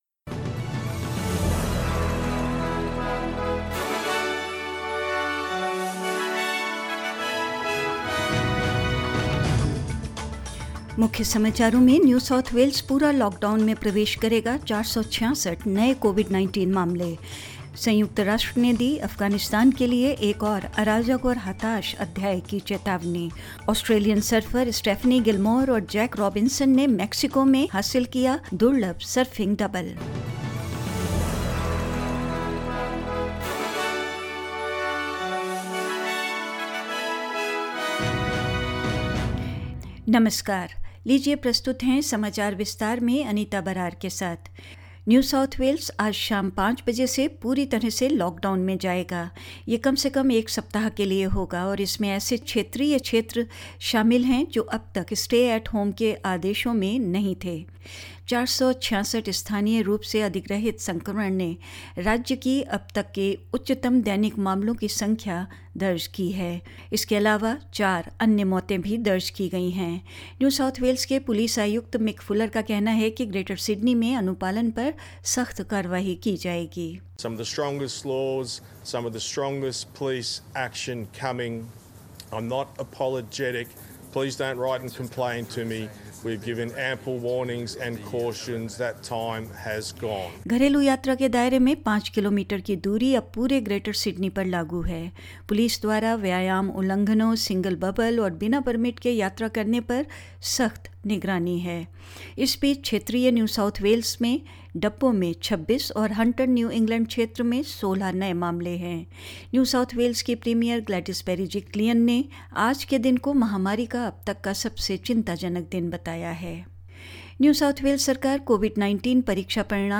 In this latest SBS Hindi News bulletin: All of New South Wales to enter lockdown, as 466 new COVID cases are recorded; The Taliban makes further gains in Afghanistan, as the United Nations warns of another chaotic and desperate chapter for the country; Australian surfers Stephanie Gilmore and Jack Robinson achieve a rare surfing double in Mexico and more